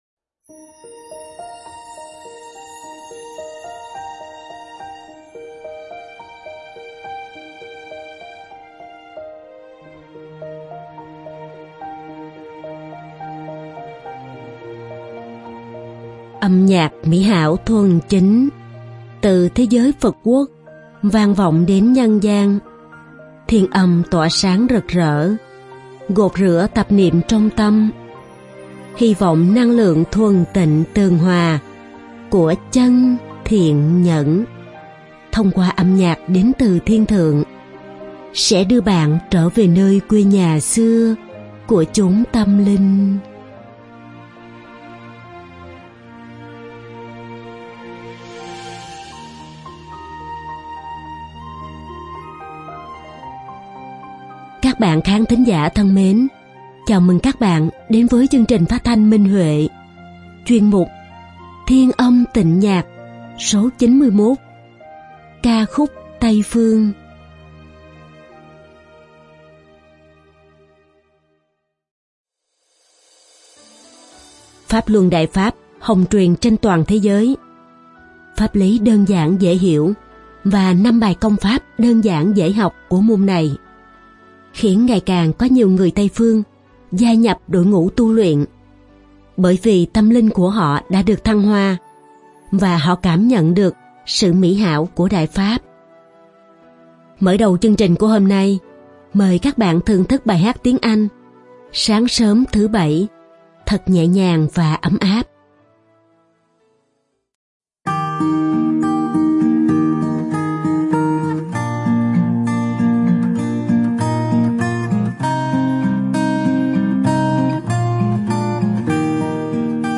Ca khúc Tây phương.